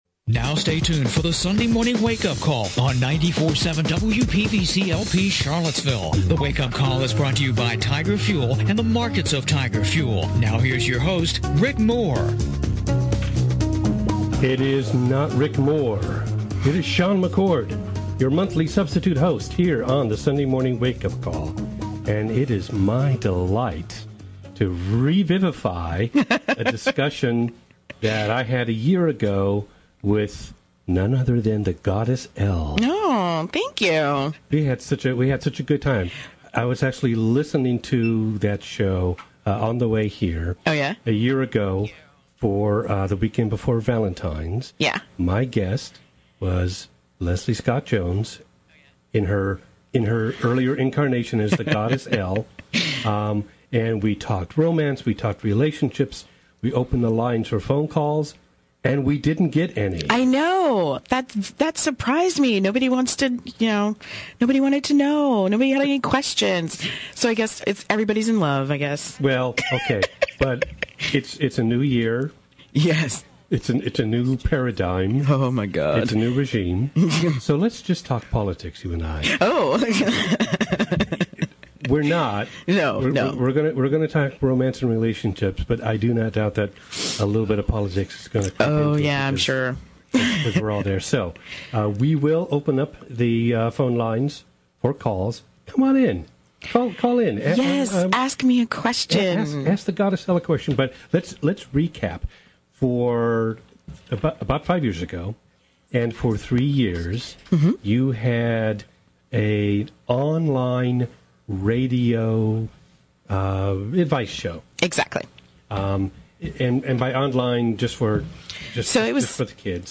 The Sunday Morning Wake-Up Call is heard on WPVC 94.7 Sunday mornings at 11:00 AM.